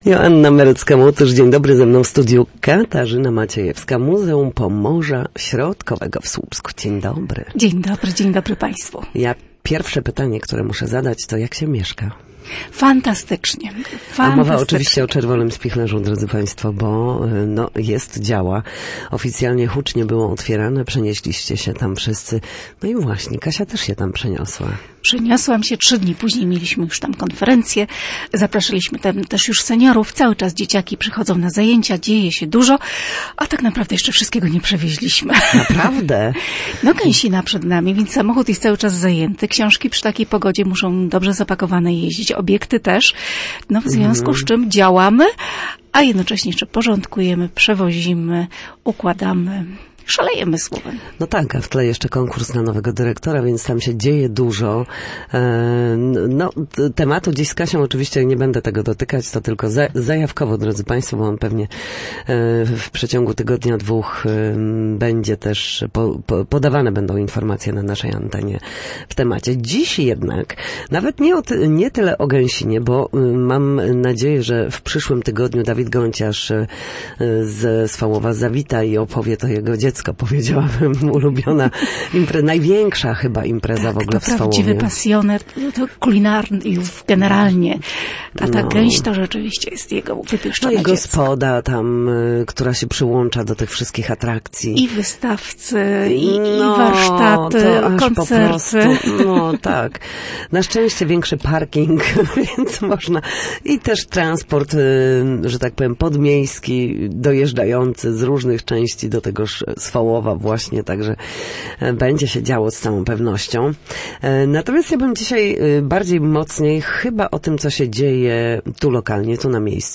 Na antenie opowiadała o wyjątkowej wystawie poświęconej Zofii Stryjeńskiej – artystce, którą często nazywa się „malarką rzeczy polskich”.